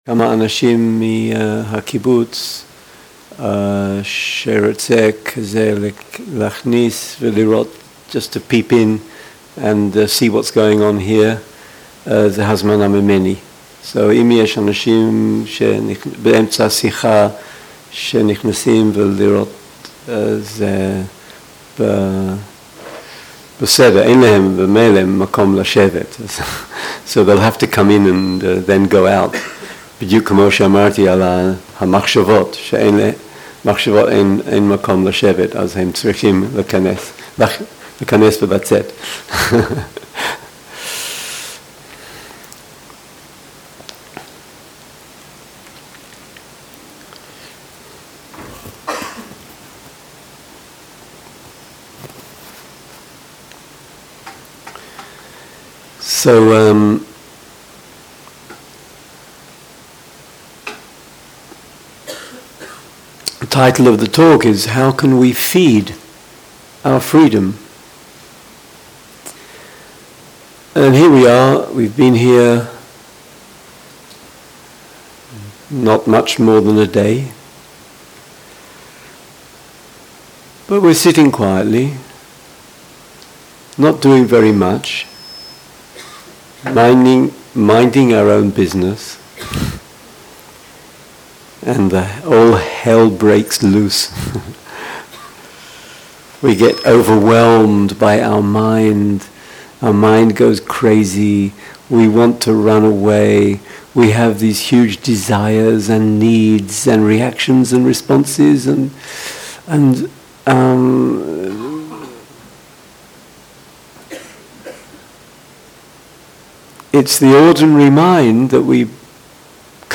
Dharma Talks